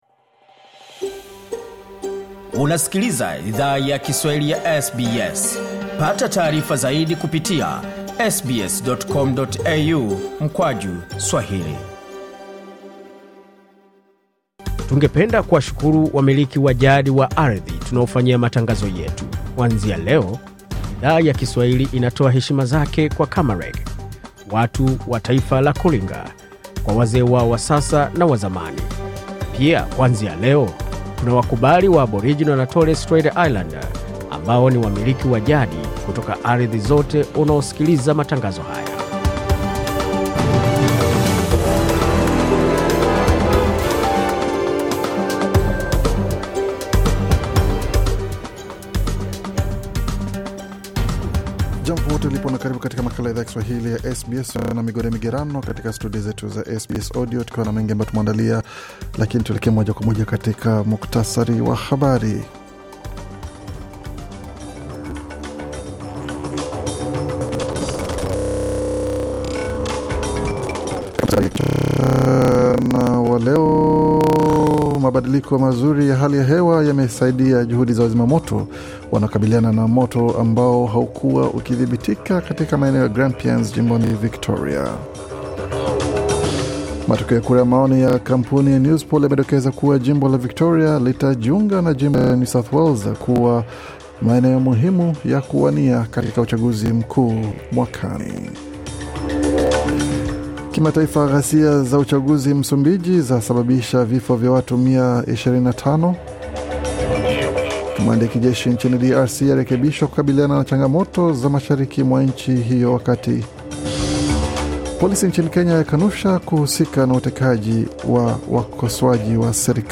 Taarifa ya habari 27 Disemba 2024